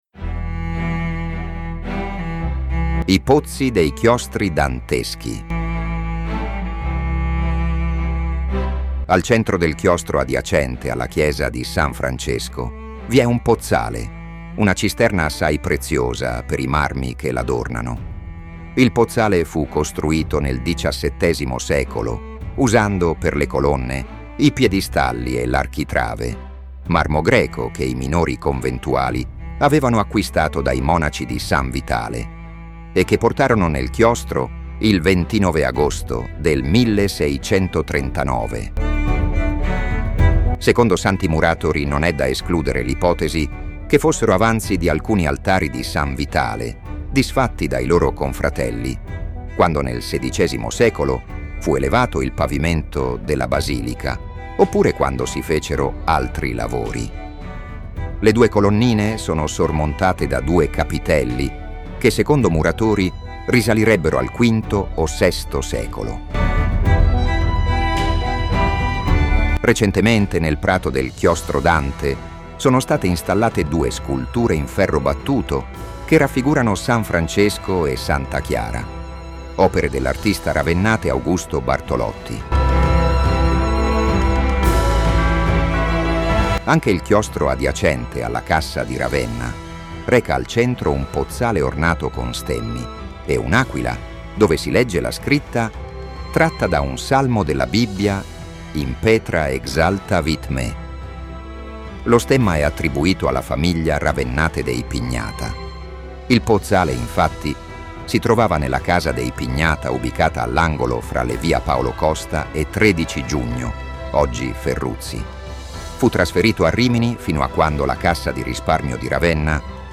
Audio_Guida
Voce: AI
I_pozzi_dei_Chiostri_Danteschi_audioguida.mp3